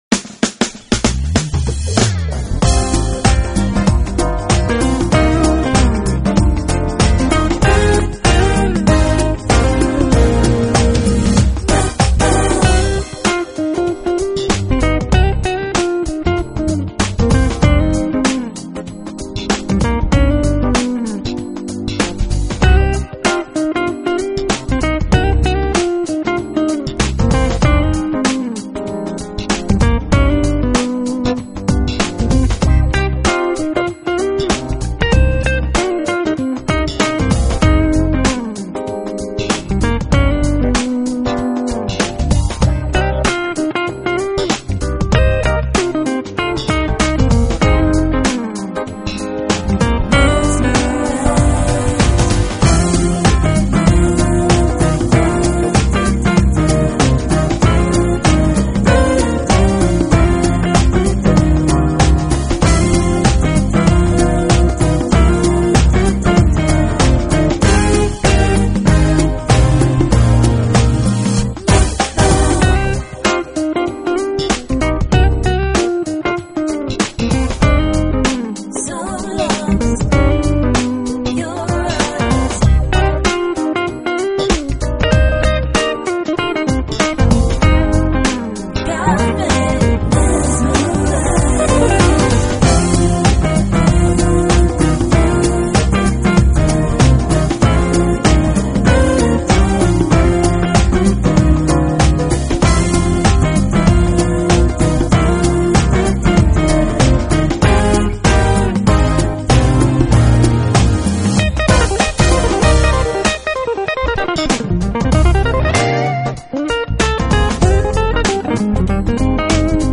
to deliver a real smooth jazz stunner.